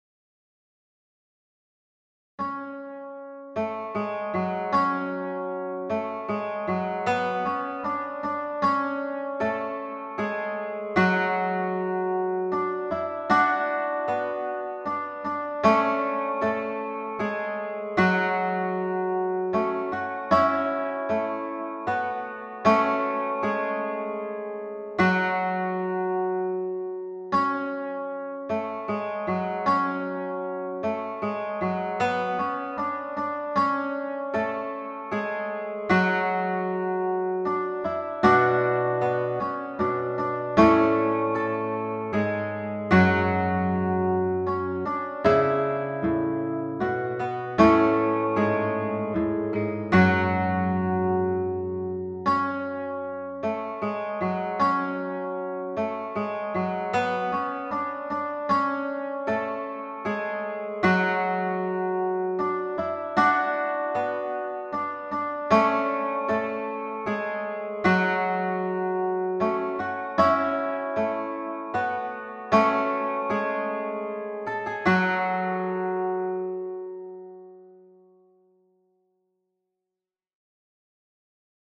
Cantio Sarmatoruthenica LXXXVII (in g)